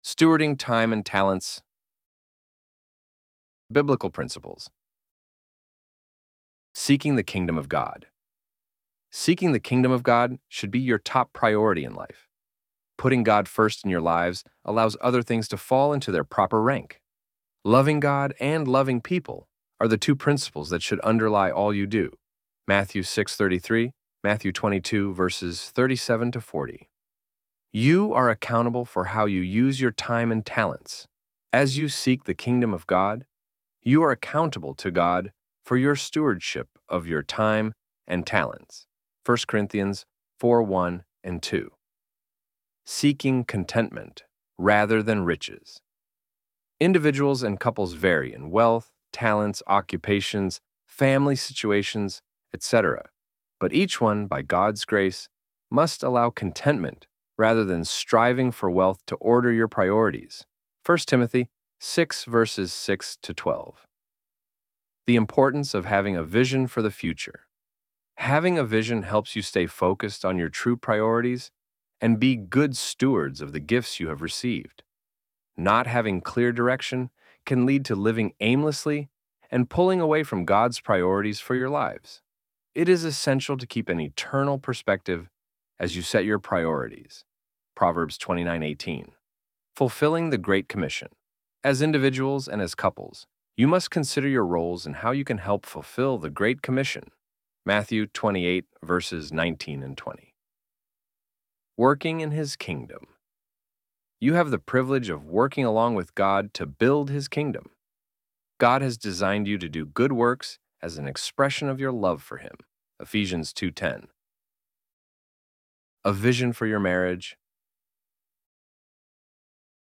ElevenLabs_Stewarding_Time__Talents_2025.pdf.mp3